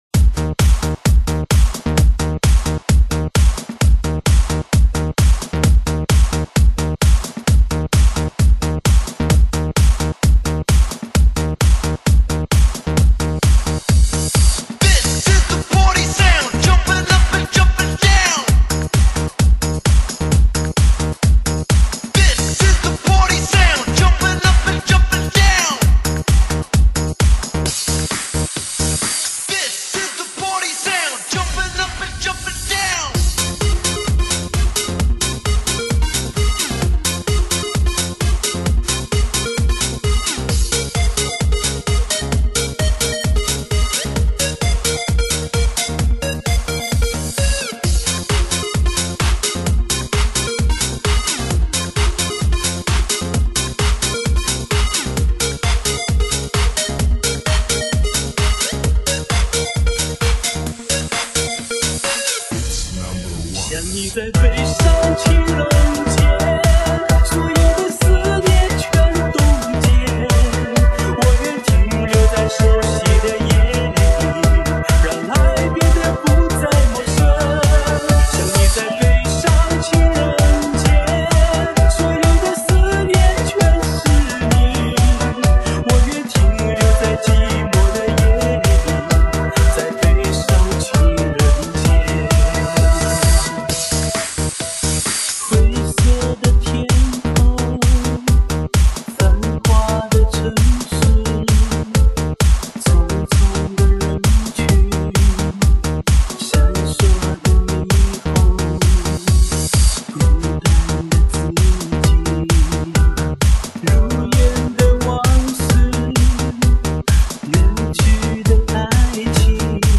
顶级HI-FI原创舞曲震憾登场